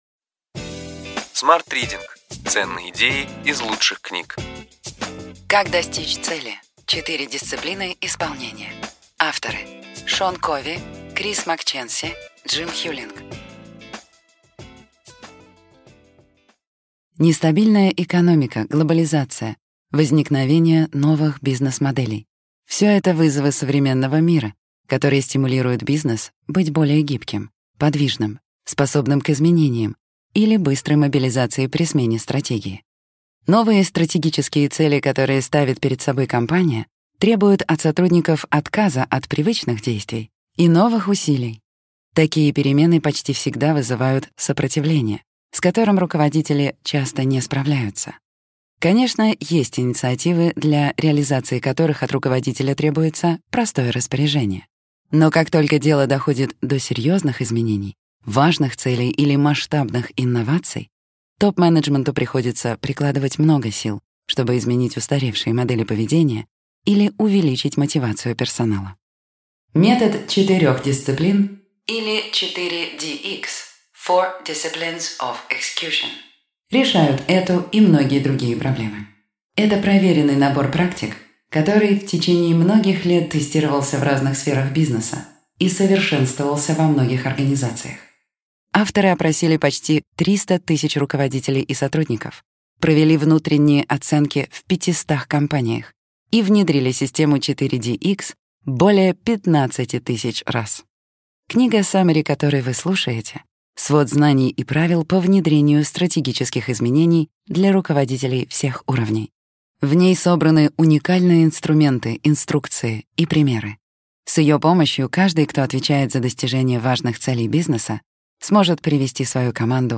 Аудиокнига Ключевые идеи книги: Как достичь цели. Четыре дисциплины исполнения.